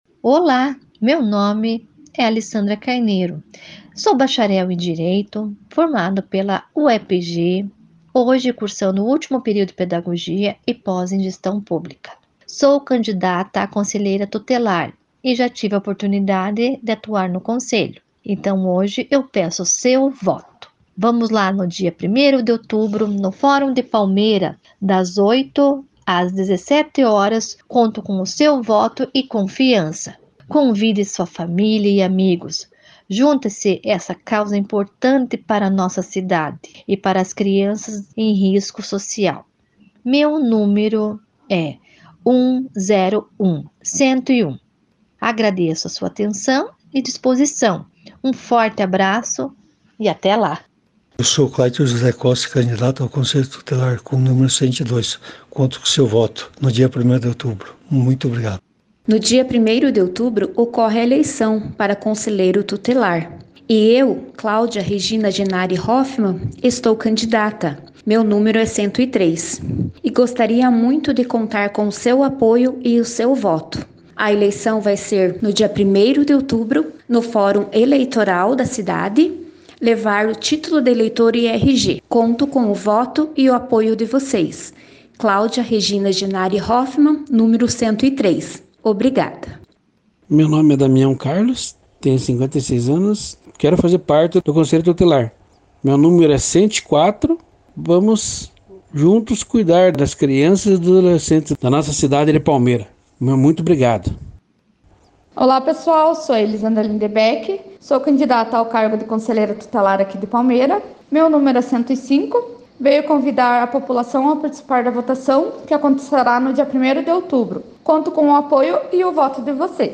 Confira  abaixo áudio que foi ao ar no Noticiário P7 desta segunda (18)com a apresentação dos candidatos a Conselheiro Tutelar e seus respectivos números.